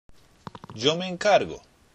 前後がつながって聞こえやすいので、
意外と聞き取りにくいですね＾＾